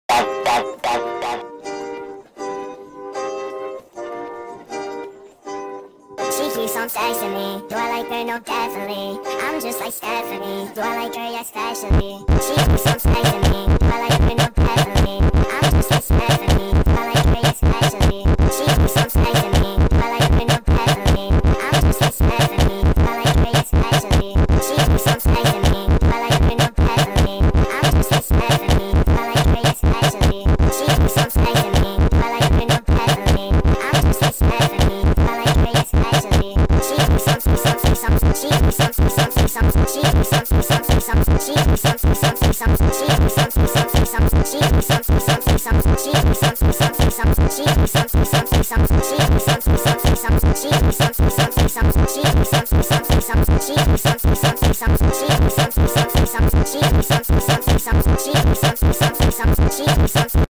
با ریتمی سریع شده
فانک